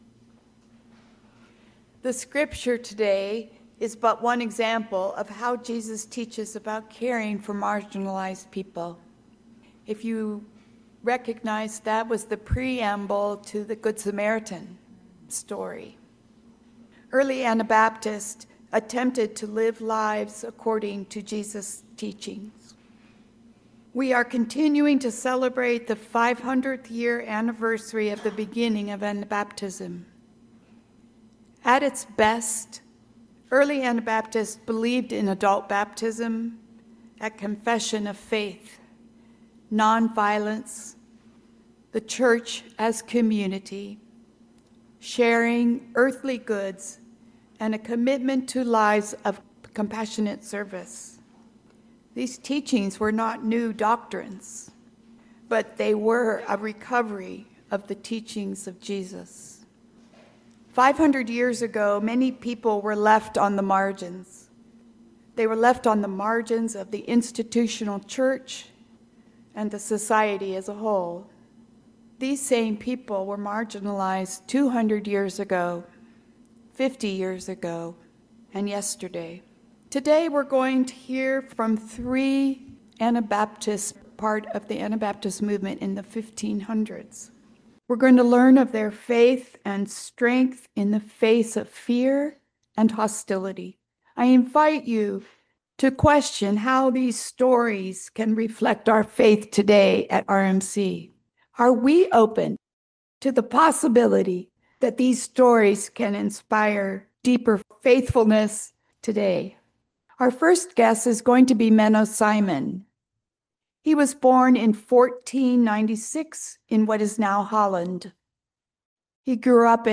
Luke 10: 25-28 This week volunteers from the RMC congregation shared dramatic readings, acting out some of the experiences of the founding figures in Anabaptist/Mennonite history that suffered persecution and even martyrdom. This continues our series celebrating 500 years of Anabaptism.
Dramatic Readings – Aug. 31, 2025